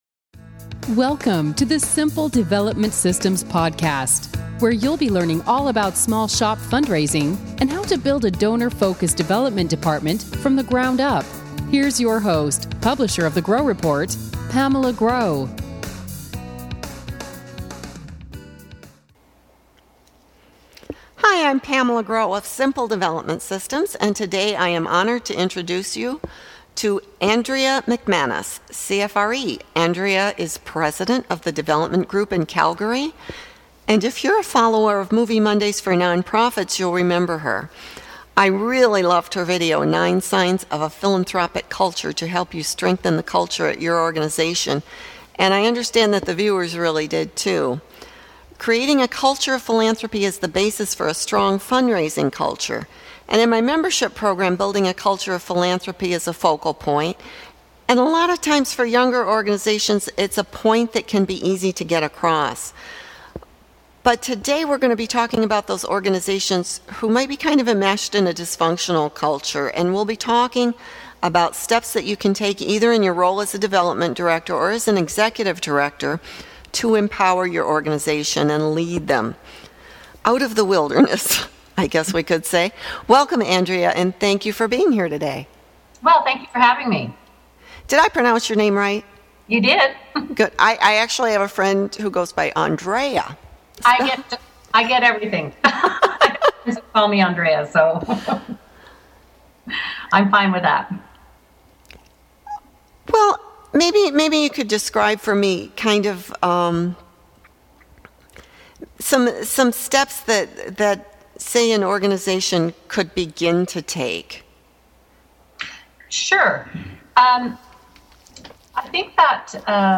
Creating your organization's culture of philanthropy | Interview